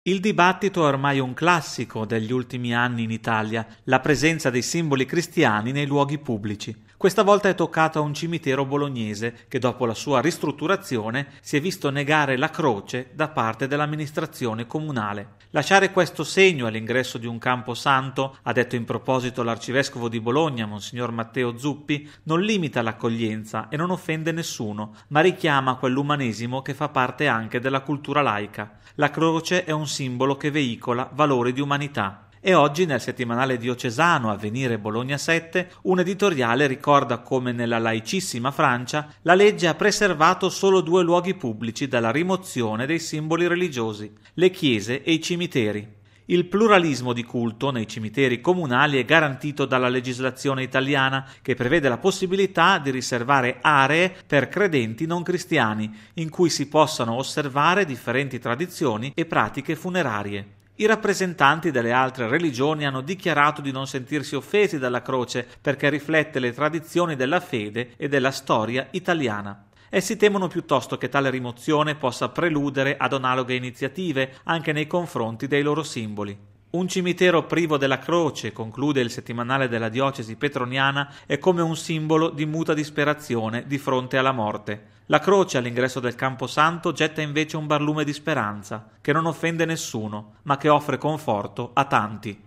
Bollettino Radiogiornale del 07/02/2016